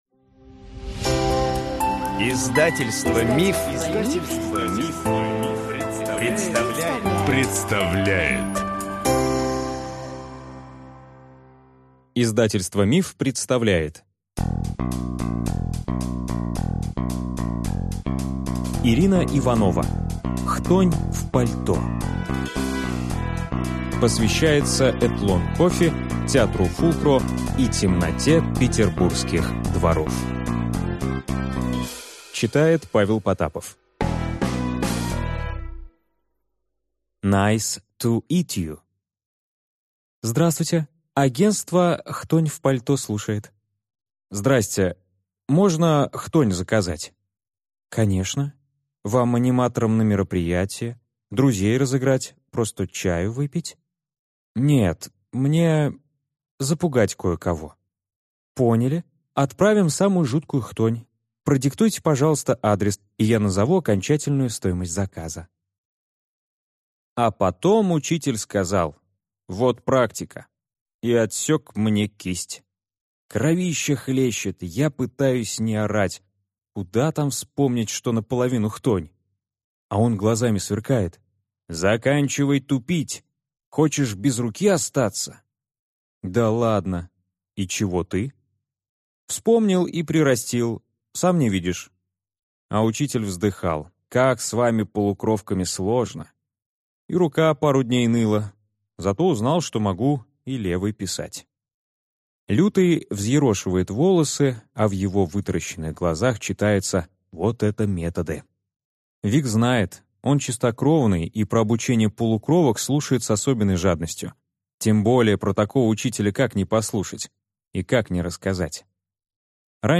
Аудиокнига Хтонь в пальто | Библиотека аудиокниг